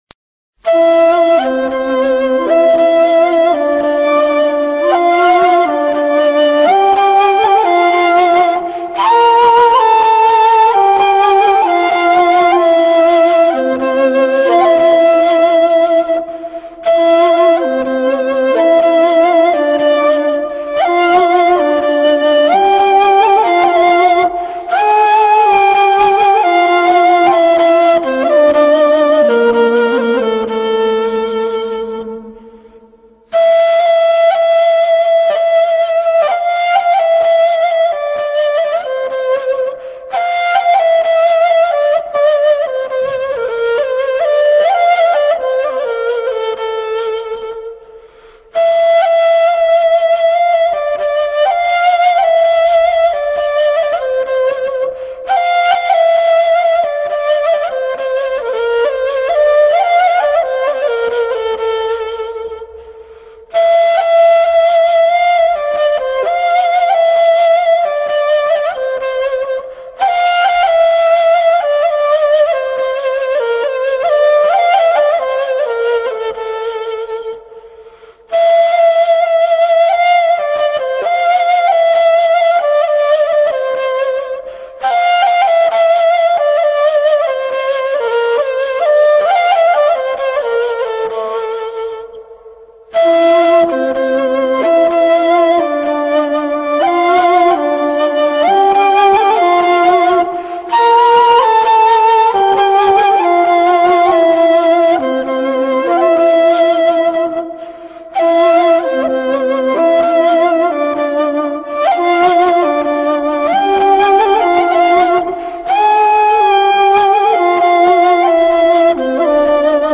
1-İLAHİ:
• Belli bir makamla söylenir.
Gel-Gör-Beni-Aşk-Neyledi-Yunus-Emre-NEY.mp3